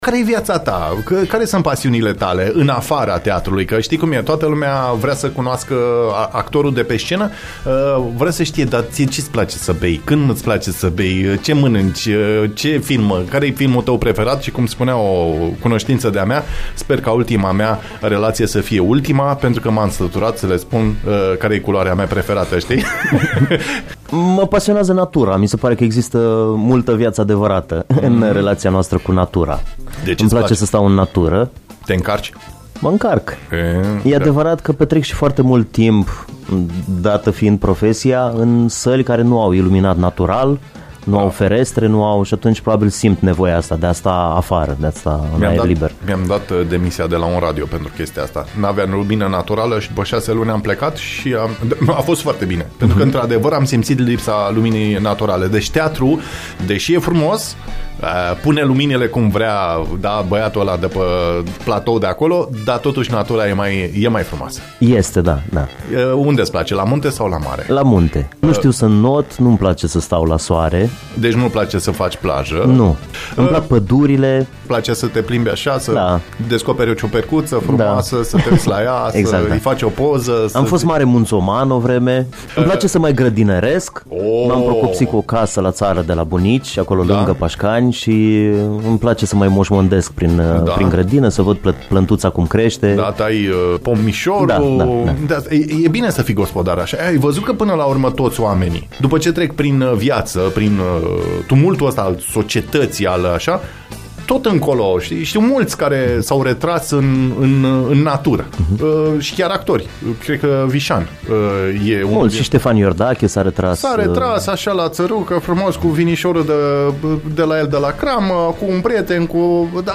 Ascultați mai jos discuții incitante despre actul cultural, colaborări, pasiuni, activități conexe, echilibru psihic și fizic, relația cu publicul….